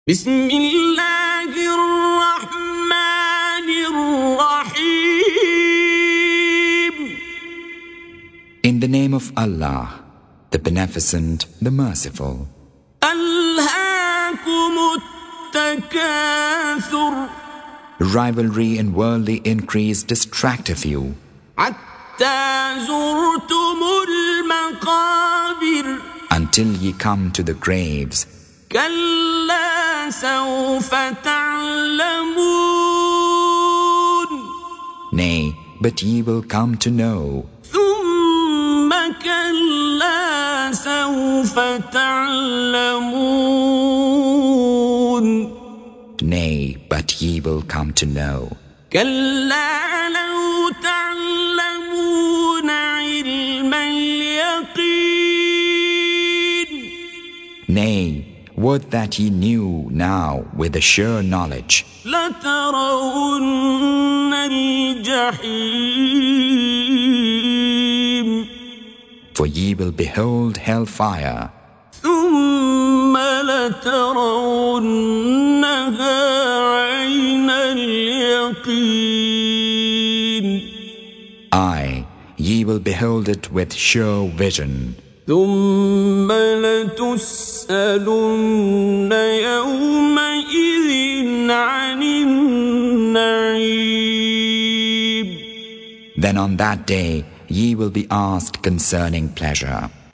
102. Surah At-Tak�thur سورة التكاثر Audio Quran Tarjuman Translation Recitation